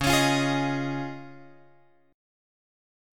C#add9 chord {x 4 3 6 4 x} chord